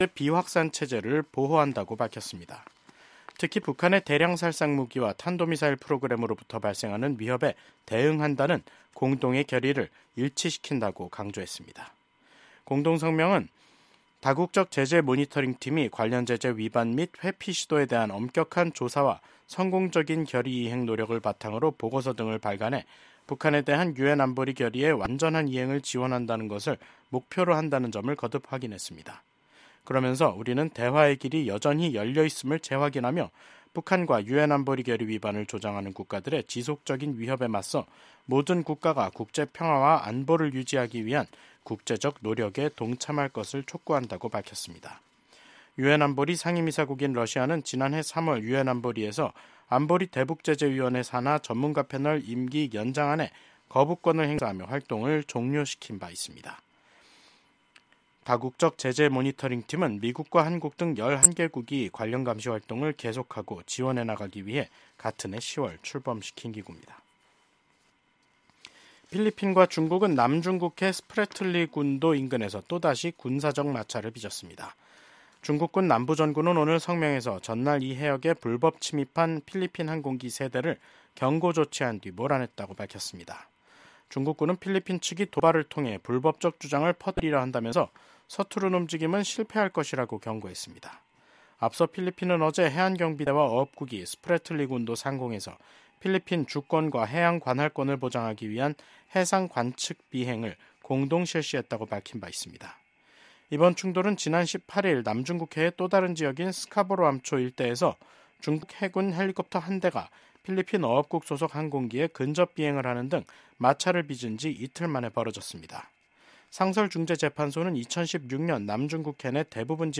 VOA 한국어 간판 뉴스 프로그램 '뉴스 투데이', 2025년 2월 21일 3부 방송입니다. 미국 백악관 국가안보보좌관은 도널드 트럼프 대통령이 김정은 북한 국무위원장을 비롯한 독재자들에 맞설 수 있는 유일한 인물이라고 밝혔습니다.